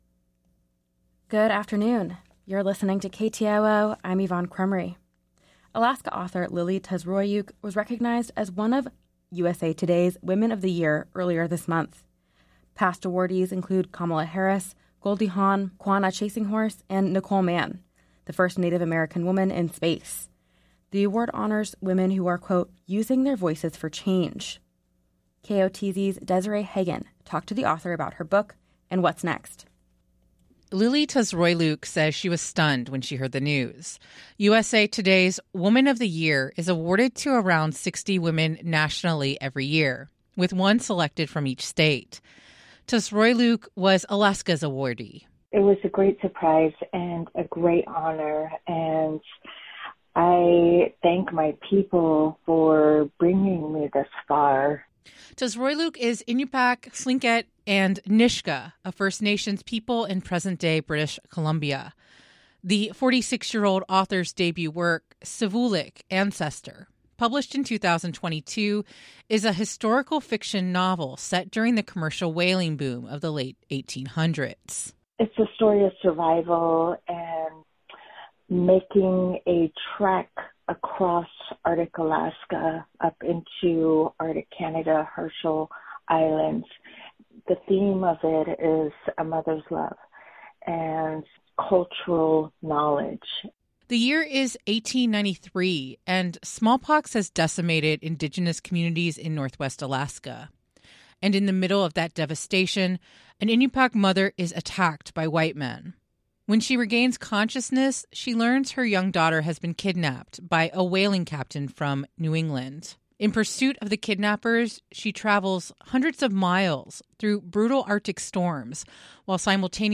Newscast – Monday, March 25, 2024